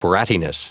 Prononciation du mot brattiness en anglais (fichier audio)
Prononciation du mot : brattiness